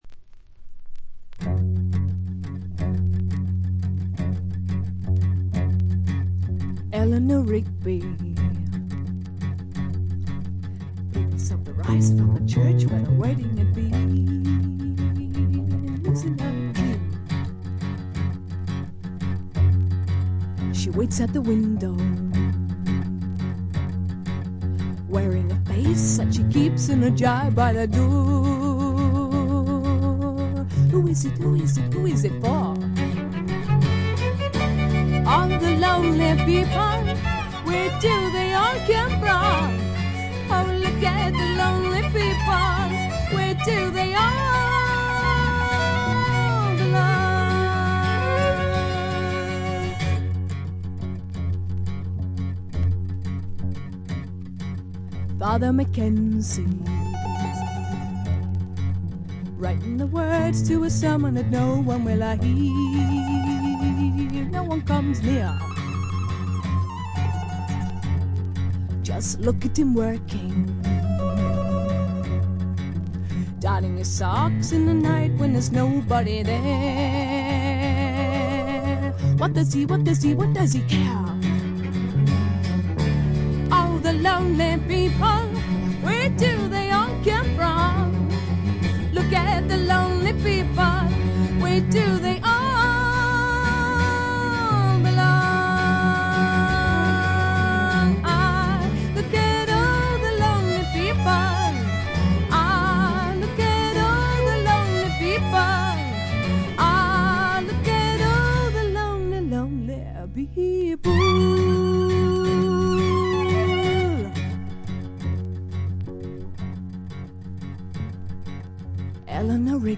試聴曲は現品からの取り込み音源です。
Cello